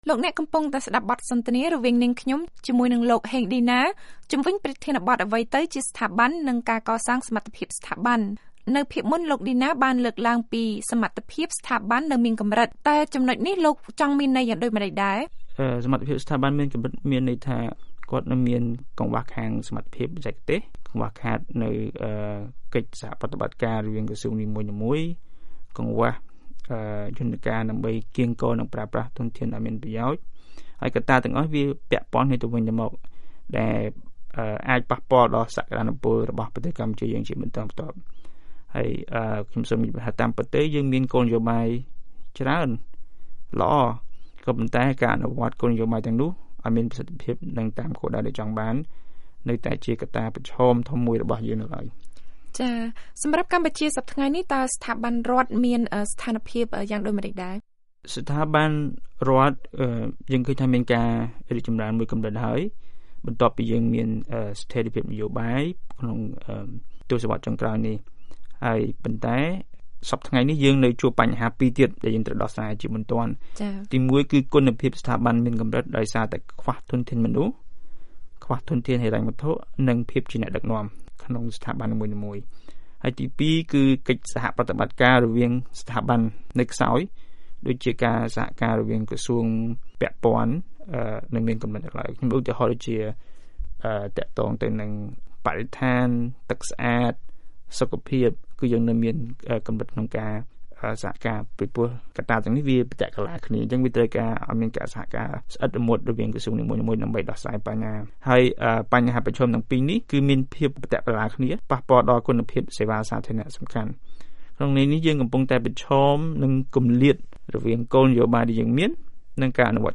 បទសម្ភាសន៍៖ សារៈសំខាន់នៃការកសាងសមត្ថភាពស្ថាប័ន (ភាគ៣)